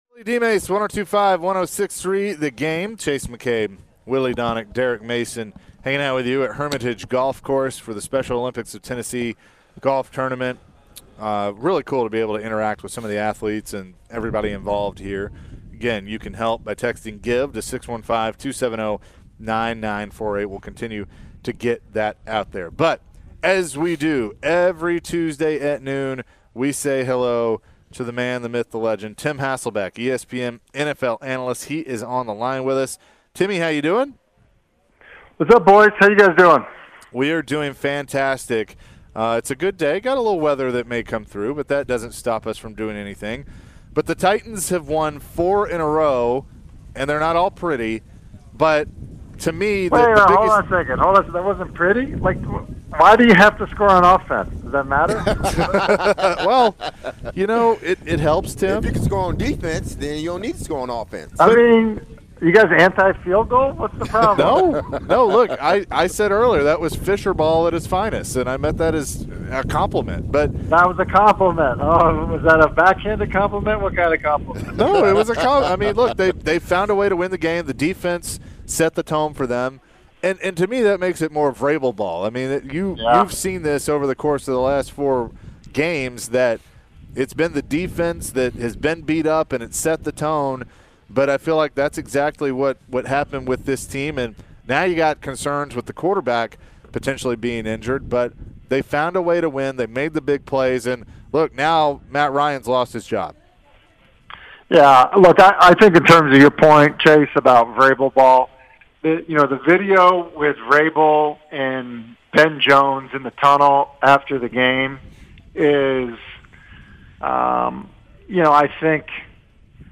Tim Hasselbeck interview (10-25-22)